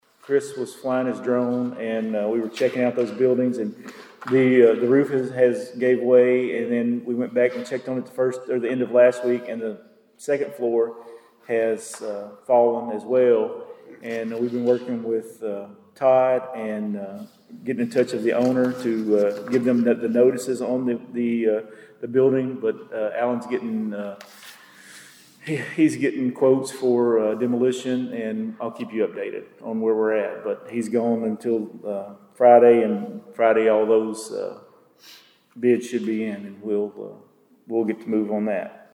At Monday night’s meeting, the Princeton City Council received updates on local issues, including trash and limb pickups and a roof collapse at a downtown building.
Mayor Brock Thomas gave a code enforcement update on the roof collapse at a building marked by cones across from City Hall on West Main Street